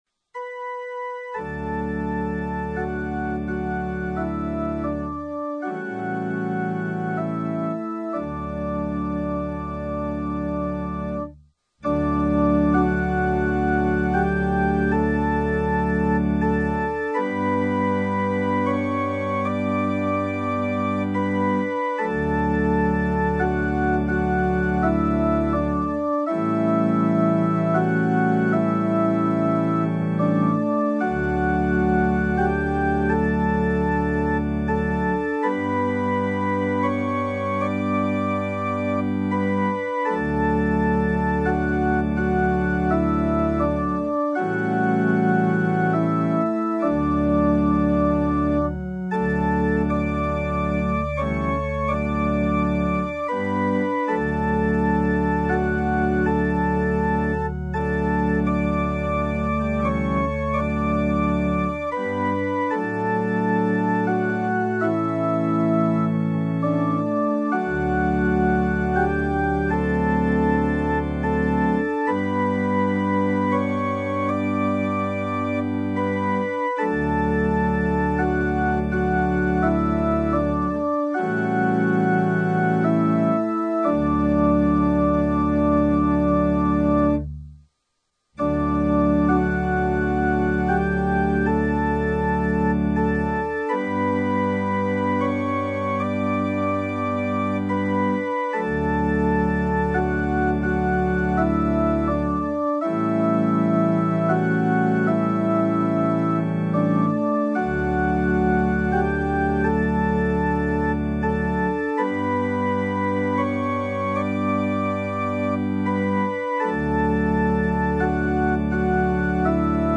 Le ton de Ré majeur, est celui de l'amour de Vénus, et peut être comparé à un diapason.
Opening_Hymn.mp3